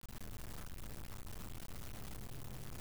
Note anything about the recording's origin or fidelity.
Weird noise added when saving .Wav as a unassinged 8 bit PCM So a while ago I suddenly been having this issue where when I save a .Wav as an unassigned 8 bit PCM, some weird noise gets added to the file.